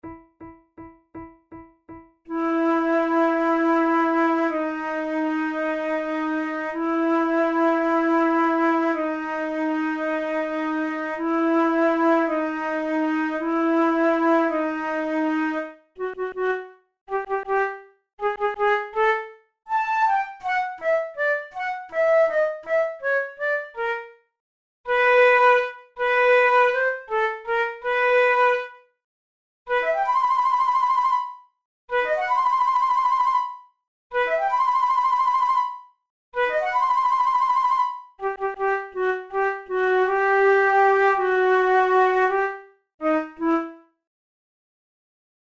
KeyE minor
Tempo54 BPM
Contemporary, Fanfares, Written for Flute